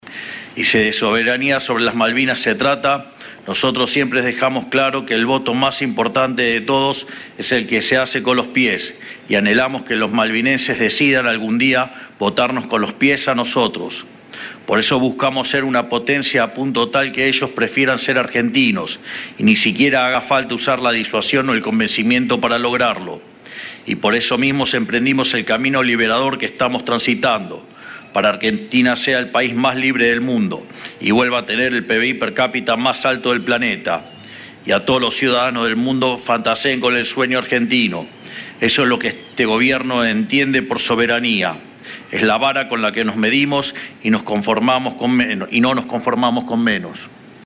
Milei emite un discurso donde reconoce a los "malvinenses" que habitan las Islas Malvinas y genera debate.
Durante un acto conmemorativo por el Día del Veterano y de los Caídos en la Guerra de Malvinas, el mandatario afirmó: «El voto más importante de todos es el que se hace con los pies y anhelamos que los malvinenses decidan algún día votarnos con los pies a nosotros. Buscamos ser una potencia para que ellos prefieran ser argentinos».